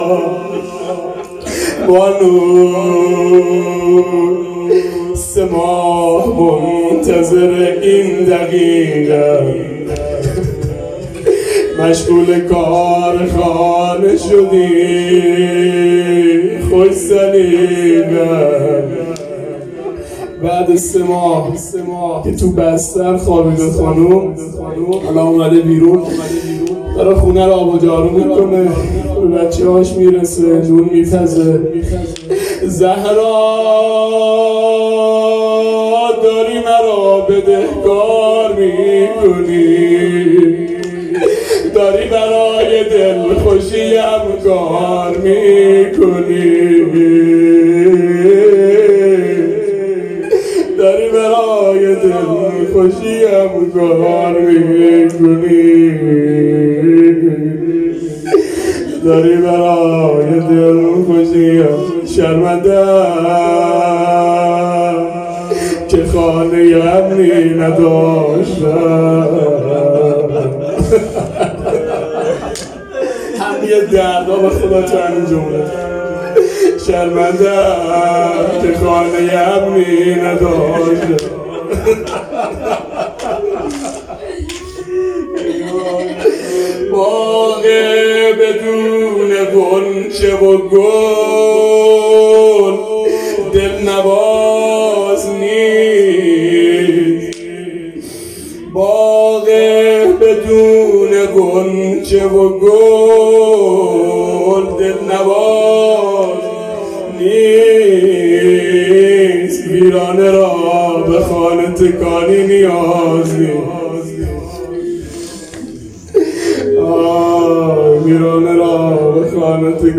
روضه | بانو سه ماه منتظر این دقیقه ام